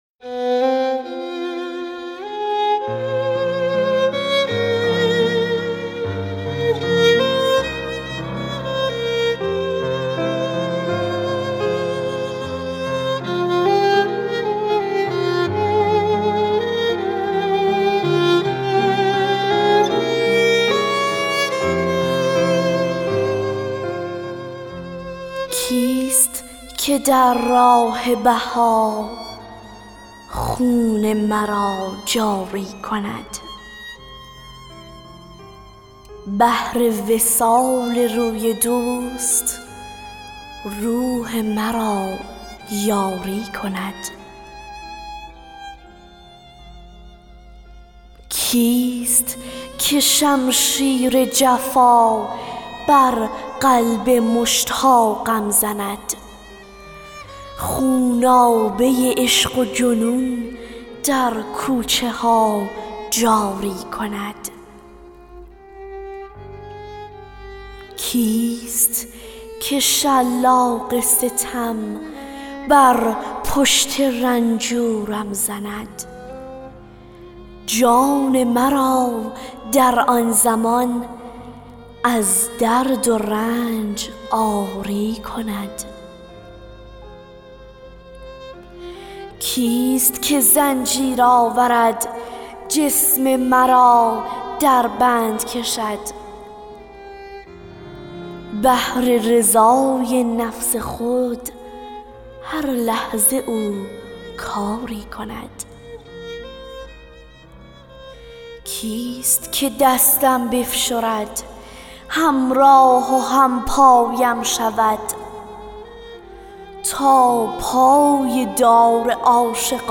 دکلمه اشعار همراه با موسیقی